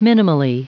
Prononciation du mot minimally en anglais (fichier audio)